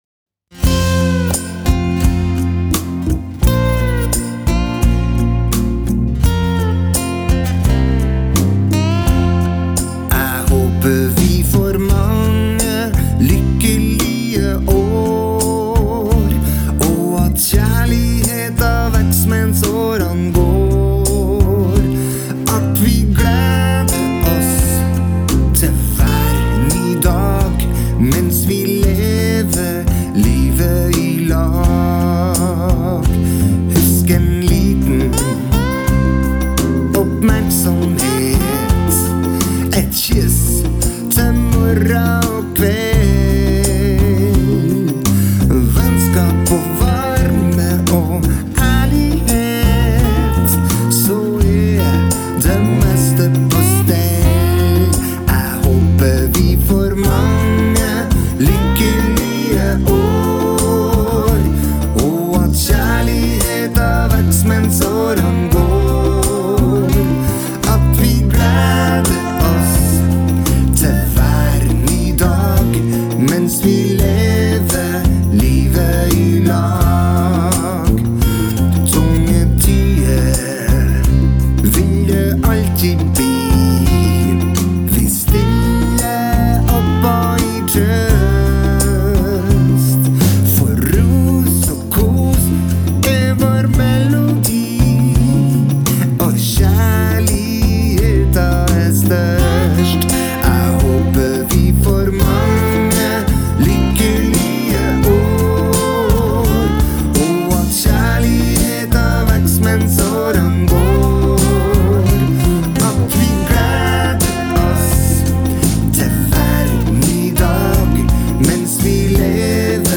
Det høres at jeg har danseband-erfaring…